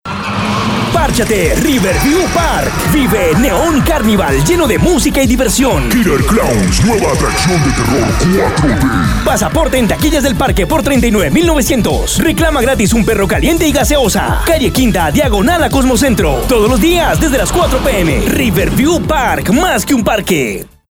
Voz comercial para radio